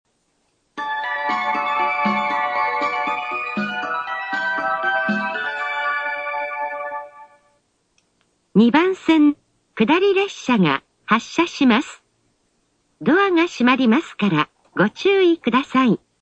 列車の本数が少ないので駅員放送が入ることが多く、メロディー・放送に被ることも多いです。
２番線下り発車メロディー